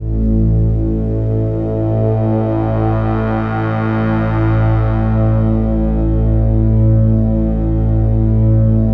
DM PAD1-29.wav